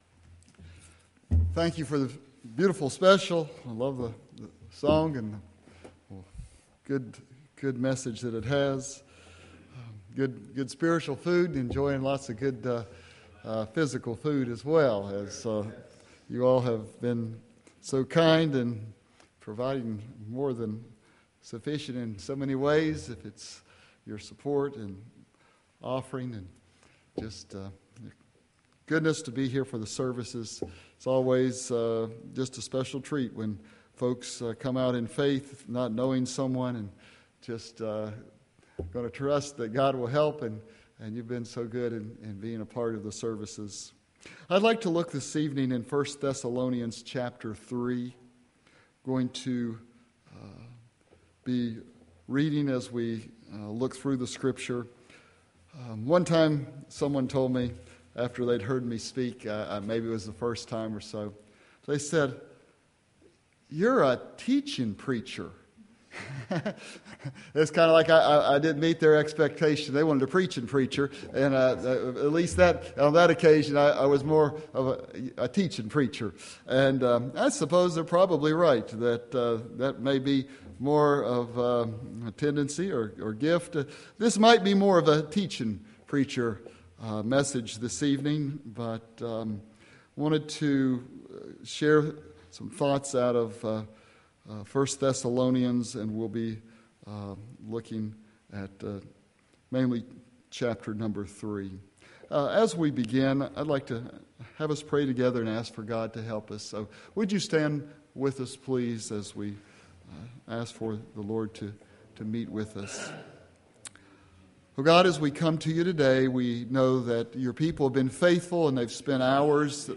Spring Revival 2016 I Thessalonians